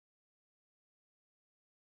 silent.wav